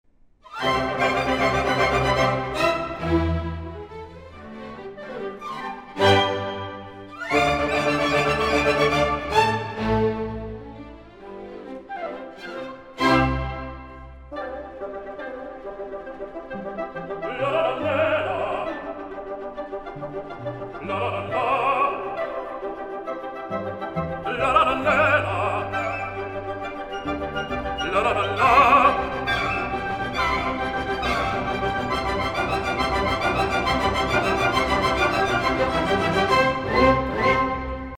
опера